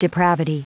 Prononciation du mot depravity en anglais (fichier audio)
Prononciation du mot : depravity